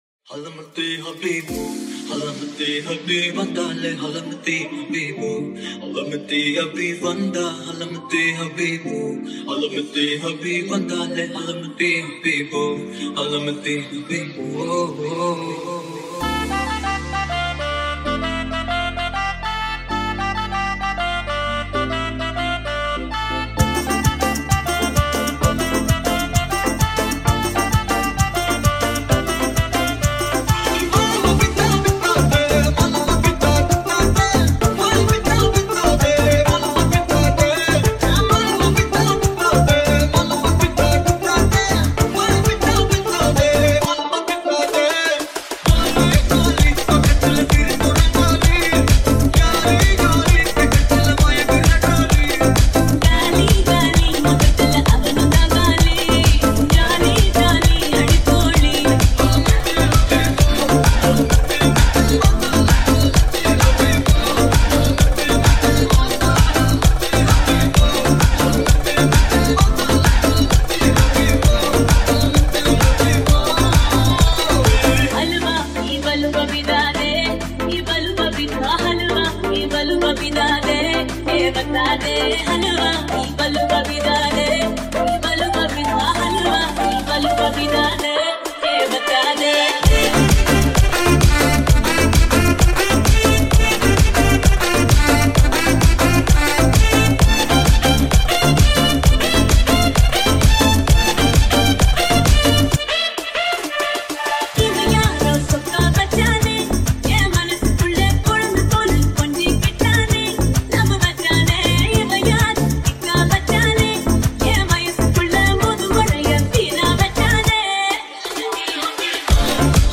EDM Remake